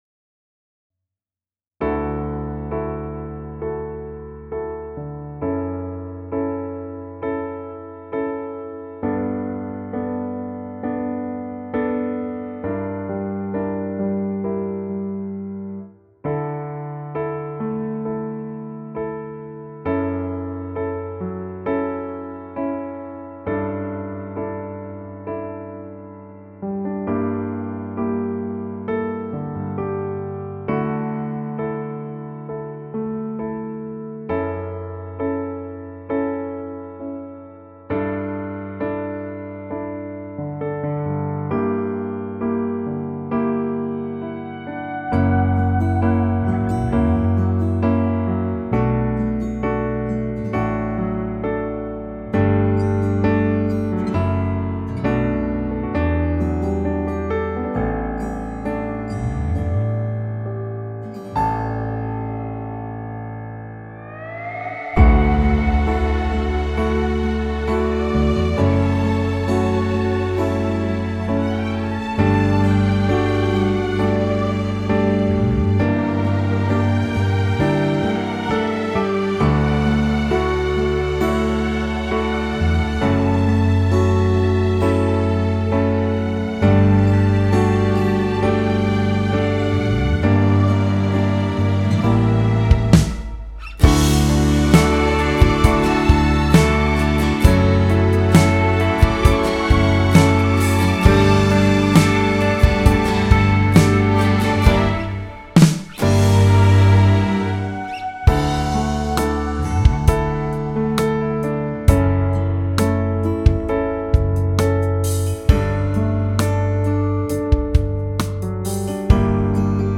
在线试听为压缩音质节选
歌曲为伴奏